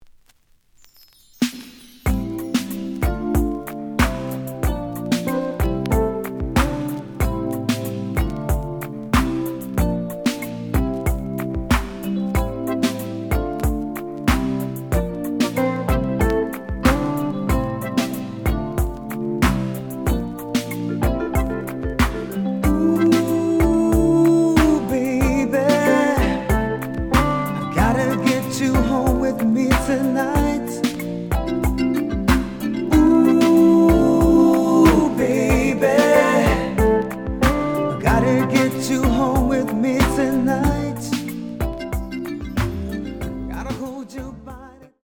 The audio sample is recorded from the actual item.
●Genre: Disco